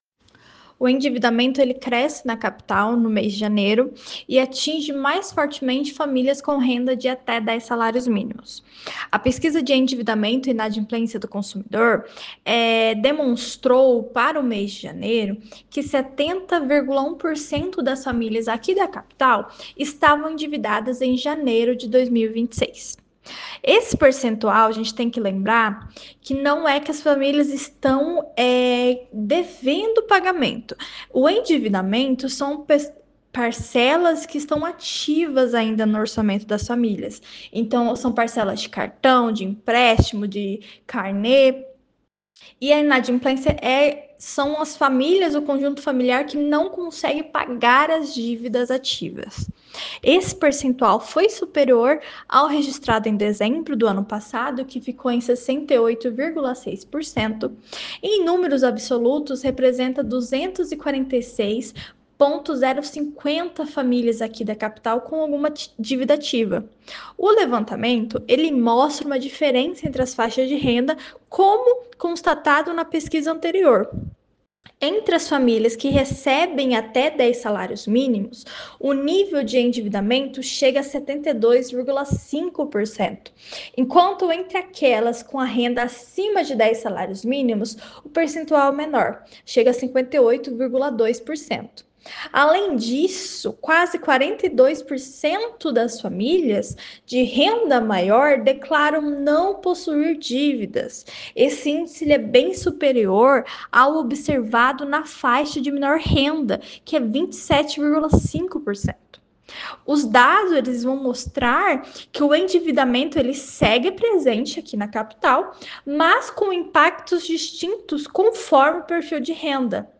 Em entrevista ao programa “Agora 104″ da FM Educativa MS